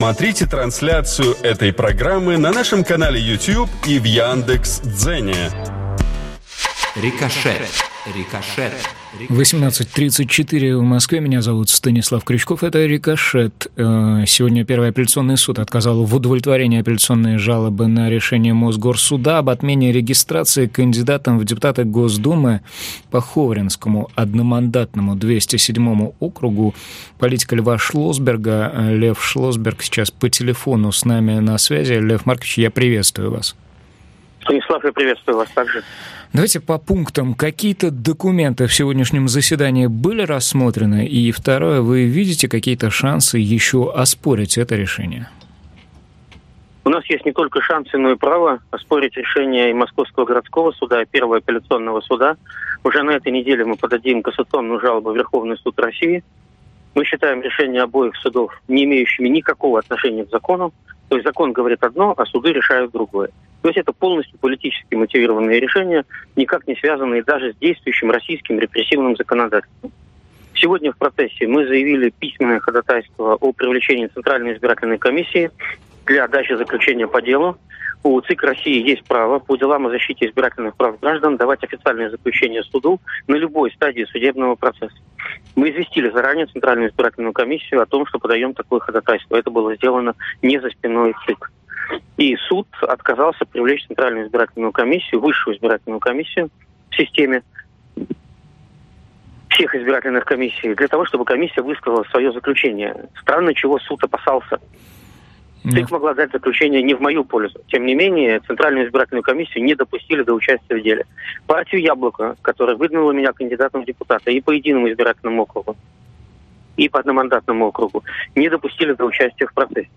Лев Шлосберг сейчас по телефону с нами на связи.